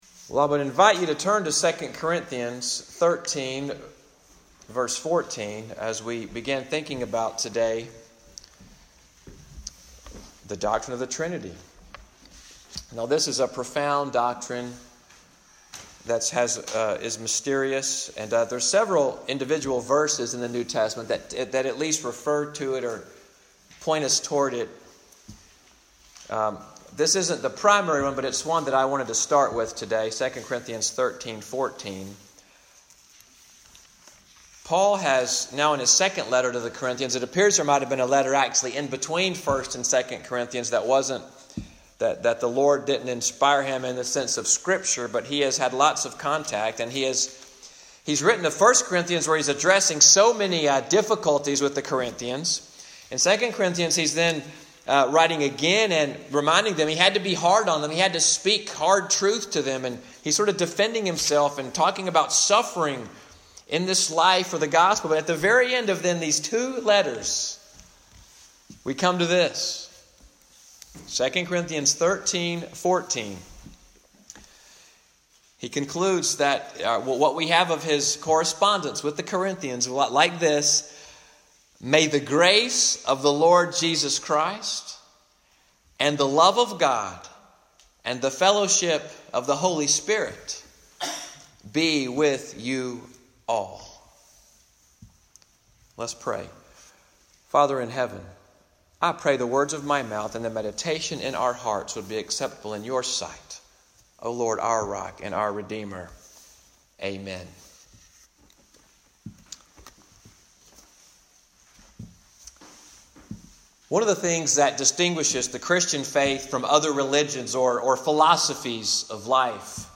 Sermon audio from Little Sandy Ridge Presbyterian Church in Fort Deposit, Alabama. Morning worship on February 17, 2019.